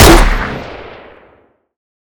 b_pong.mp3